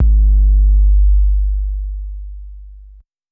808 (Different).wav